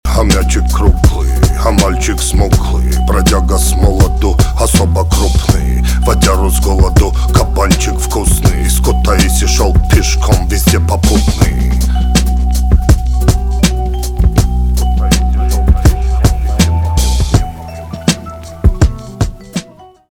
русский рэп
битовые , басы , кайфовые , спокойные , гитара